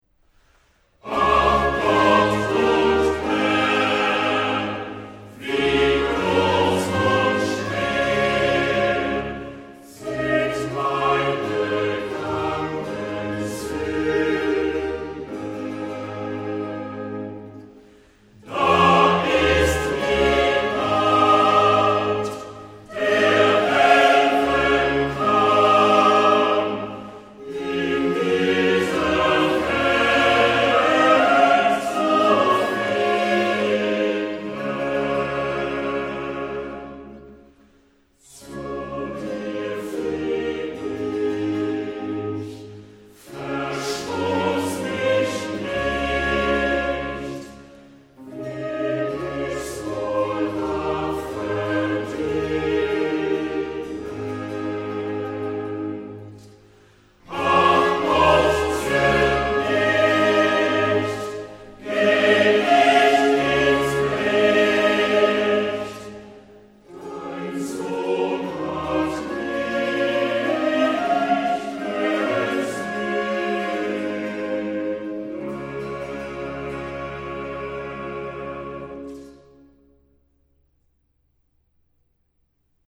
Comme ses autres oratorios-passion, elle est conçue pour la salle de concert plutôt que pour la liturgie. D’une grande richesse instrumentale, elle alterne récits, arias, chorals et chœurs, privilégiant l’expression des affects et une dramaturgie proche de l’opéra.